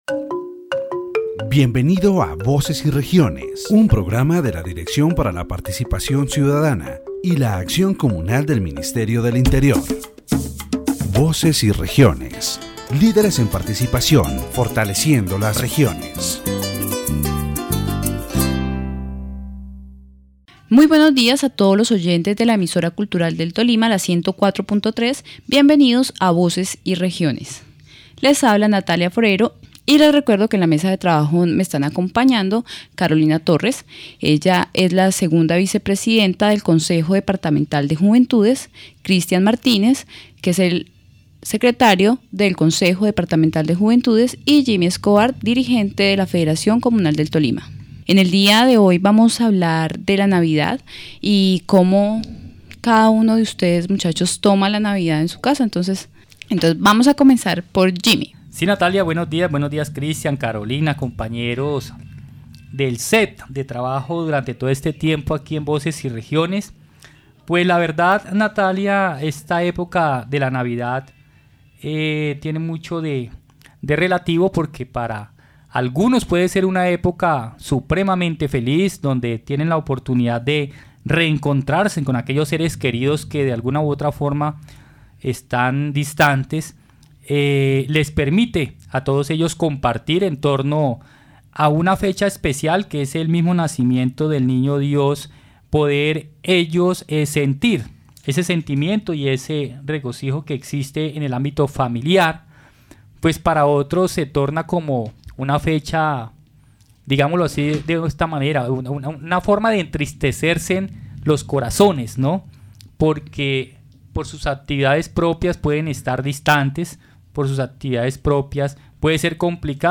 In this edition of Voces y Regiones, broadcasters from Tolima share their experiences during the Christmas season, highlighting the importance of family togetherness, cherished memories, traditional celebrations, and the region’s typical cuisine. Through their stories, they emphasize how Christmas is a time for unity, joy, and the preservation of traditions that strengthen Tolima’s cultural identity.